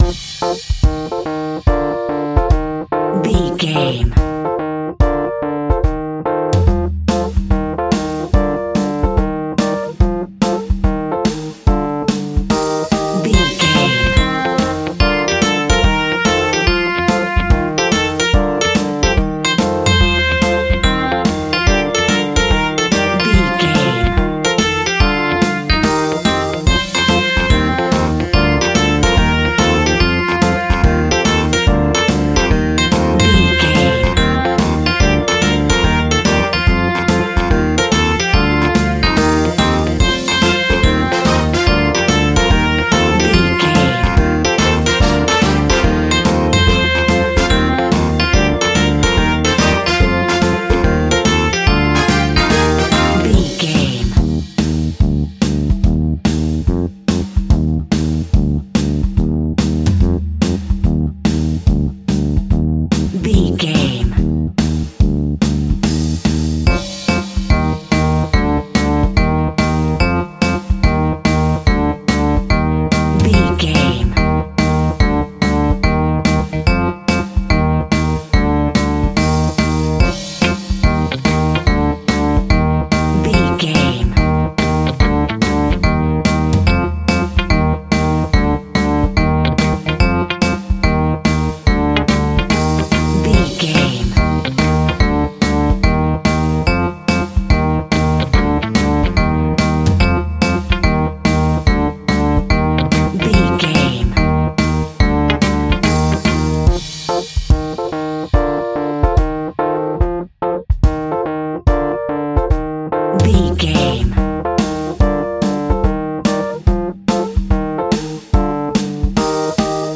Uplifting
Ionian/Major
Fast
childrens music
instrumentals
fun
childlike
cute
happy
kids piano